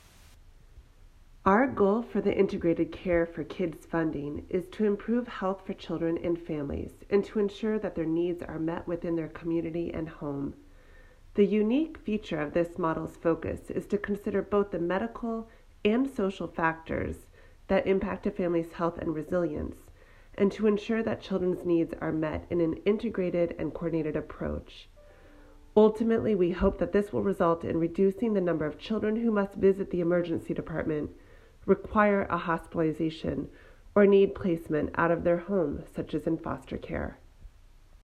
Statement from Dana Hargunani, MD, OHA chief medical officer